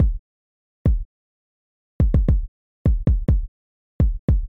陷阱序列1 踢
描述：以下是该曲目的踢脚
Tag: 105 bpm Trap Loops Drum Loops 787.67 KB wav Key : Unknown